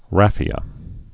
(răfē-ə)